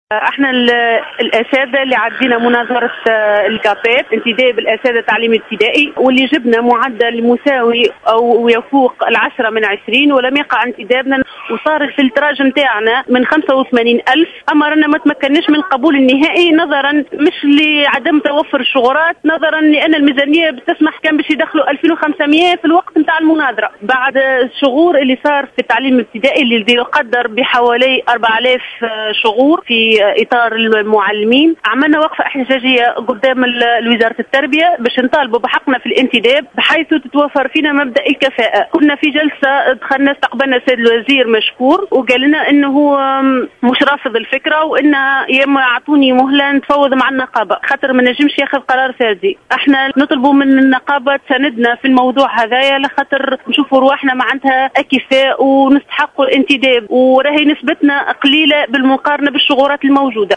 وقد بينت إحدى المحتجات في تصريح للجوهرة أف أم أنه لم يقع قبولهم نهائيا باعتبار أن ميزانية الدولة لا تسمح بذلك مضيفة أن الوزير وعدهم خلال جلسة صباح اليوم بالتفاوض مع النقابة وإيجاد صيغة لإلحاقهم خاصة مع الشغورات الموجودة في سلك المعلمين والتي بلغت 4000 شغور وفق قولها.
إحدى الأساتذة المحتجين